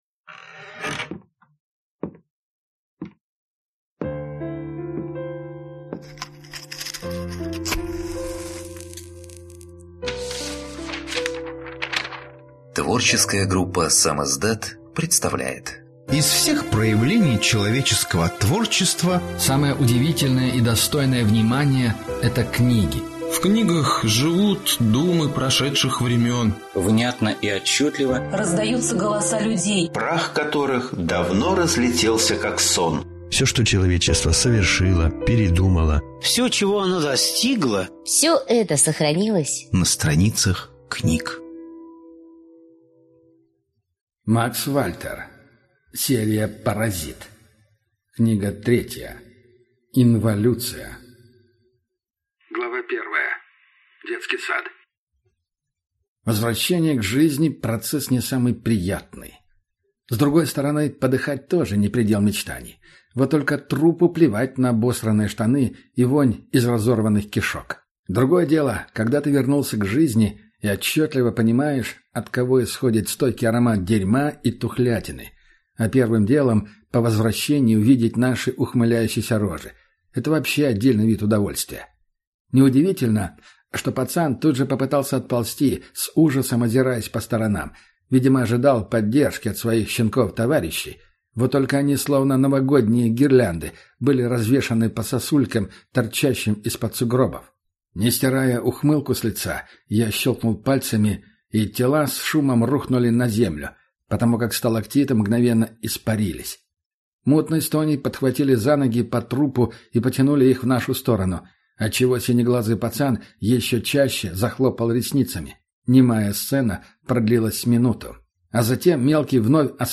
Аудиокнига Инволюция | Библиотека аудиокниг